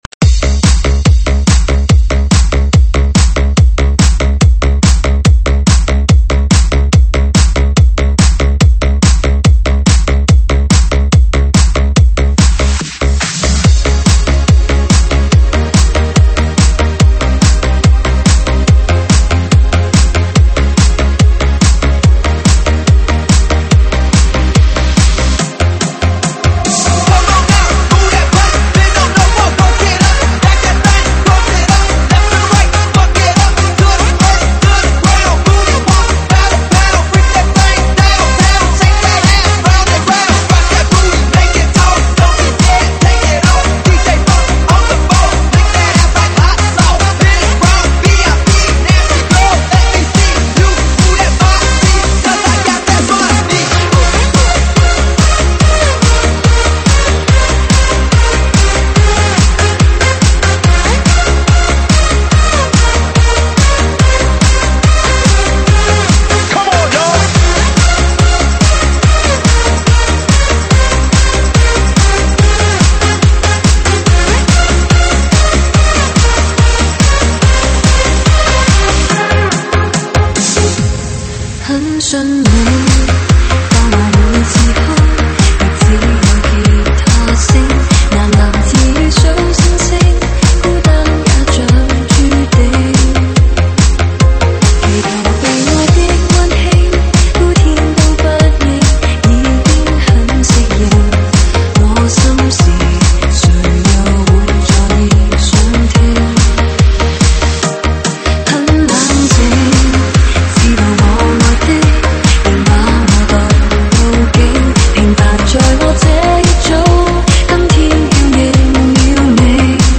舞曲类别：粤语经典